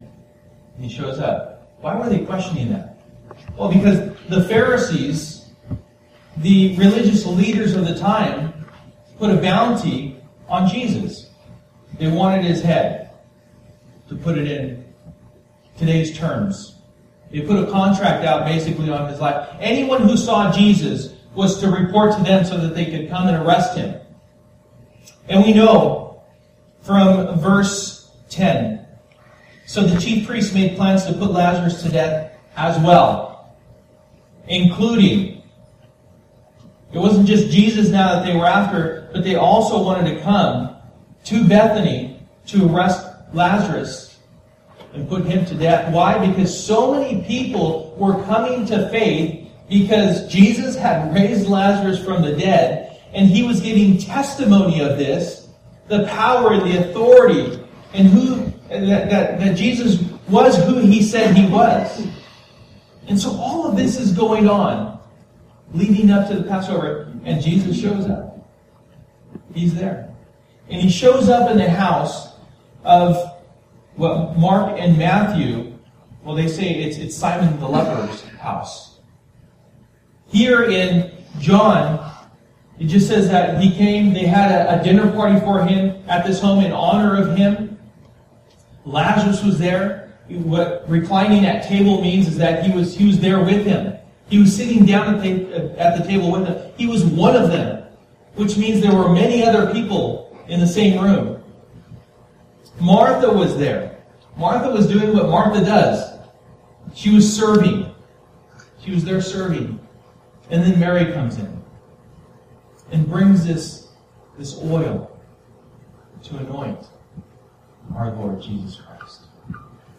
John 12:1-43 Service: Sunday Morning %todo_render% « Unbound